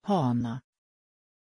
Pronunciation of Hana
pronunciation-hana-sv.mp3